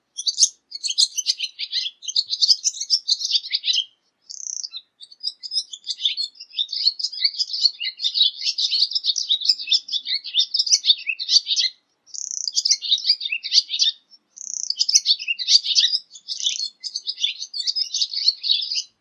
golondrinas
swallows.mp3